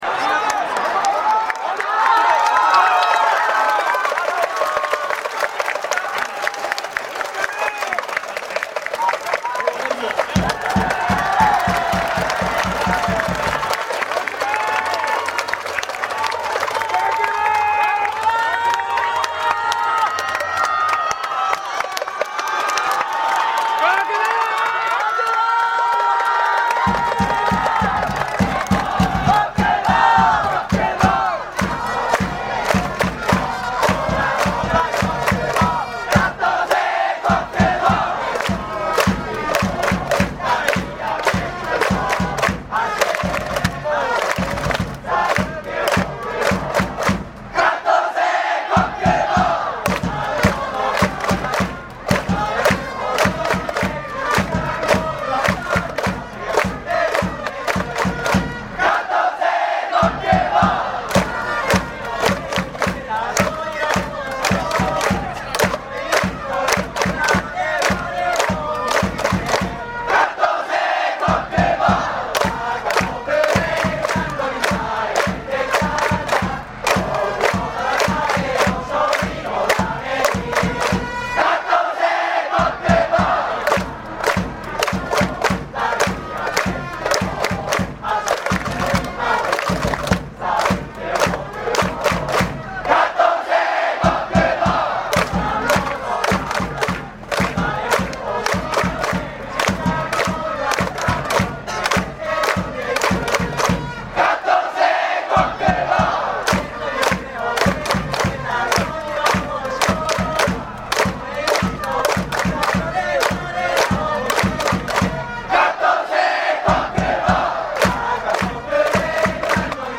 録音音源は10/5大阪ドームでの関西最終戦。
代打で1打席だけ登場した小久保選手に対して流されたもの。
関西のファンに最後の応援歌を歌わそうと、いきなりコール4回から入った応援団の粋なリードに感謝！！